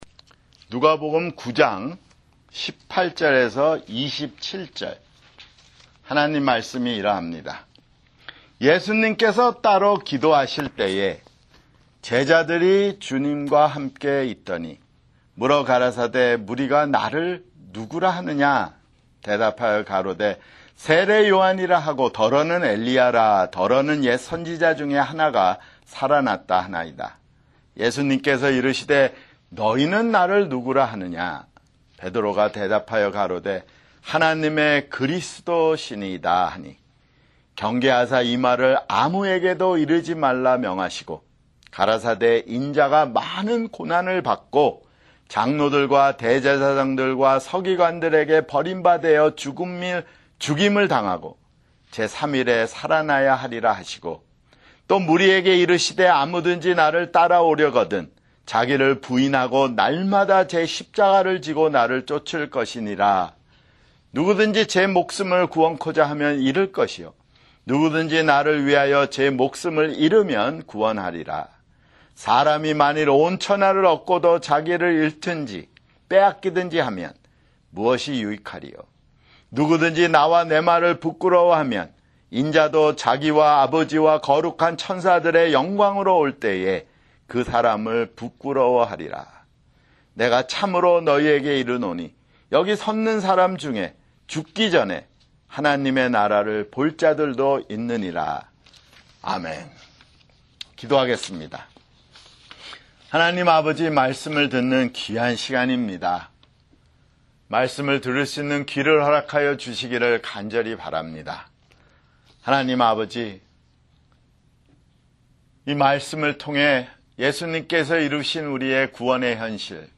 [주일설교] 누가복음 (63)